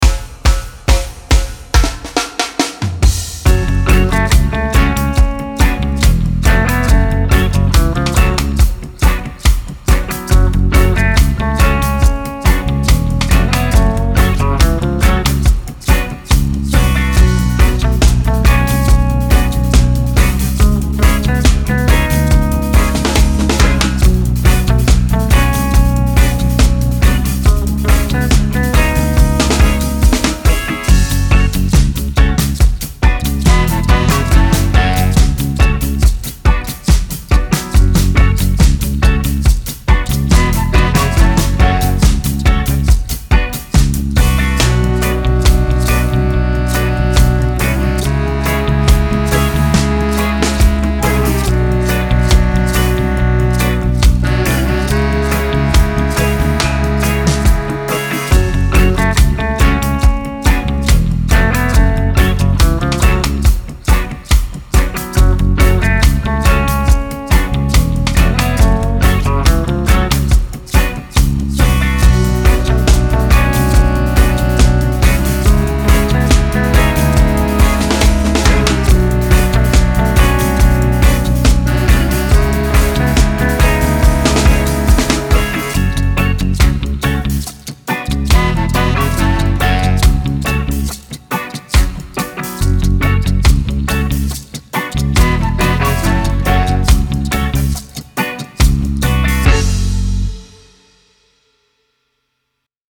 摇铃和响板
借助这套丰富多样的手工打击乐合集，为您的曲目注入充满感染力的律动。
包含乐器： 索纳哈斯、摇铃套装、谢克雷、卡希希、阿约约特斯、圭罗和卡巴萨。
Sonajas_Demo.mp3